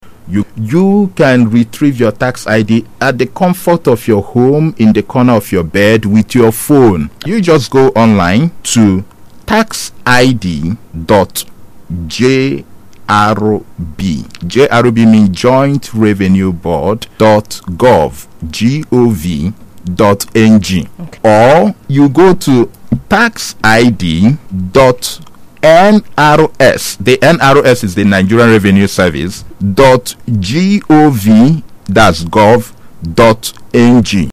disclosed this during a live conversation on Adamimogo FM